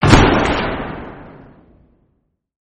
Lights_Shut_off.mp3